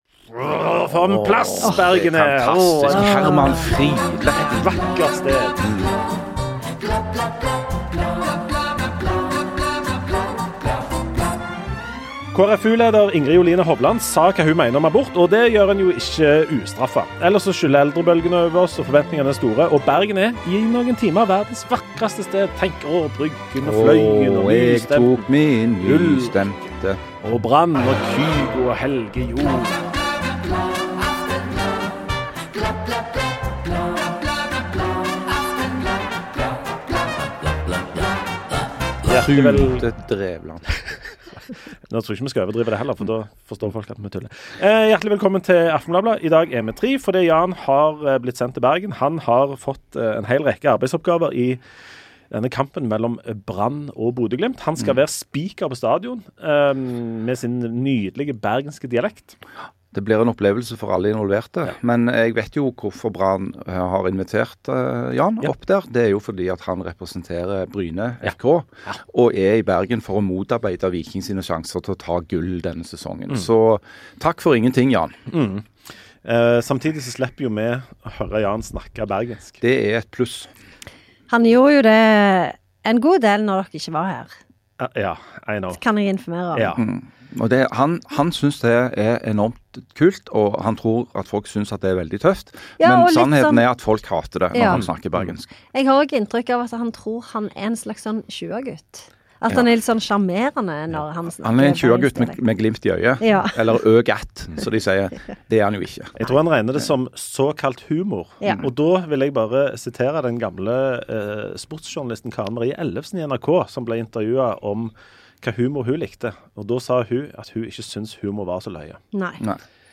Ukesaktuelt nyhetsmagasin med lause snipp.